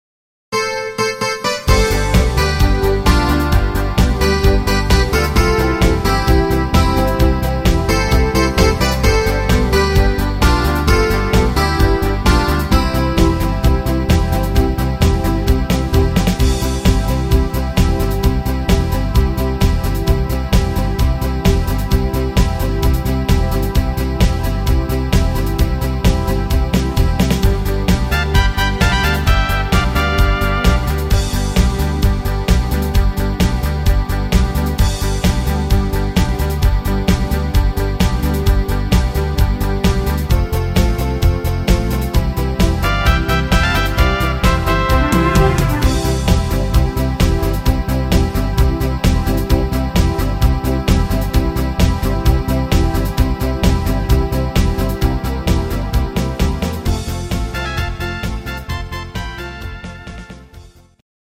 Playback abmischen  Playbacks selbst abmischen!
Rhythmus  Discofox
Art  Schlager 90er, Deutsch, Oldies